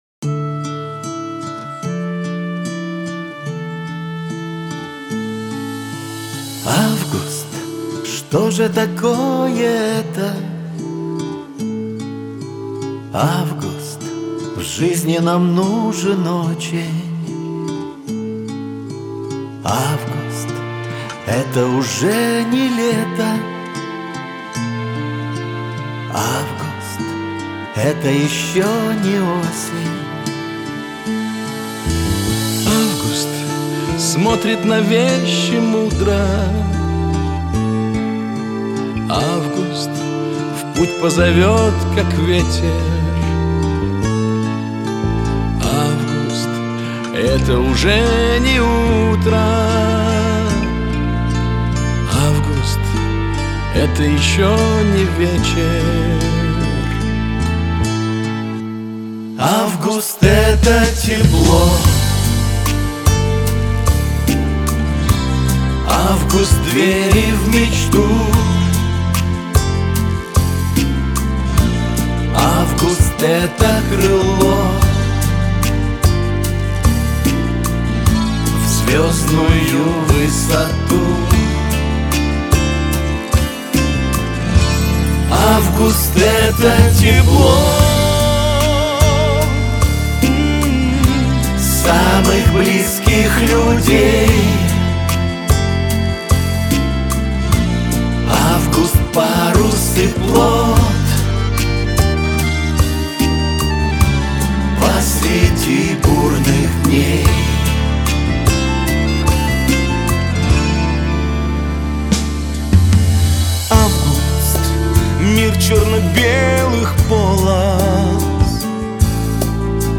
Лирика , грусть
Шансон
дуэт